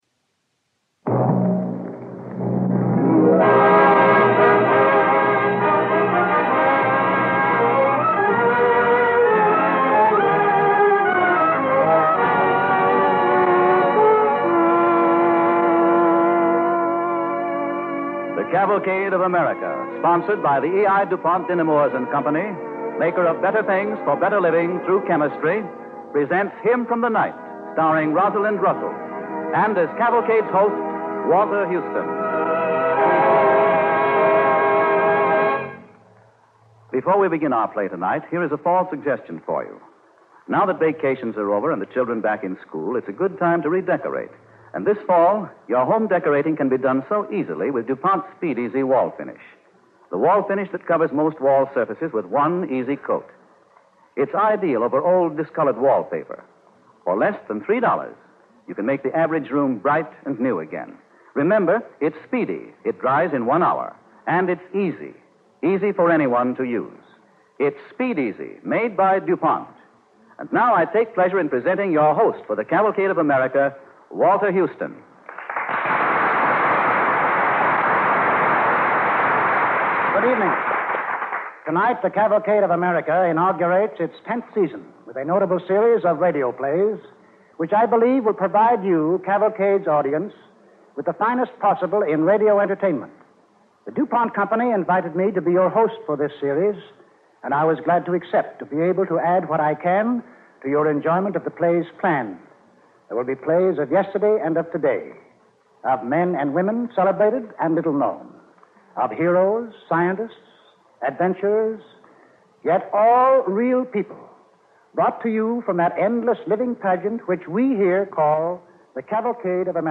starring Rosalind Russell with host Walter Houston
Cavalcade of America Radio Program